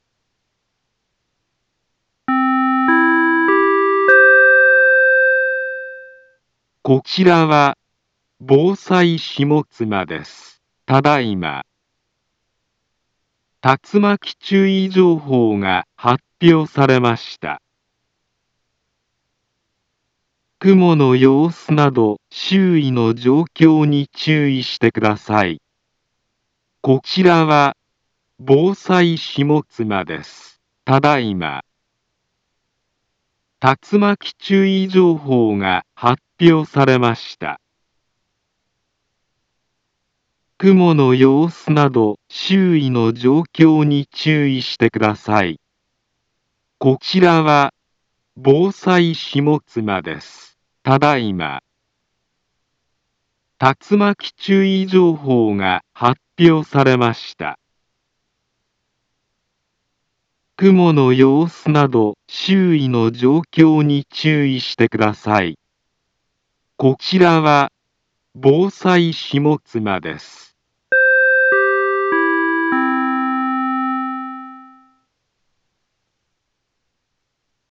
Back Home Ｊアラート情報 音声放送 再生 災害情報 カテゴリ：J-ALERT 登録日時：2025-06-23 17:14:39 インフォメーション：茨城県北部、南部は、竜巻などの激しい突風が発生しやすい気象状況になっています。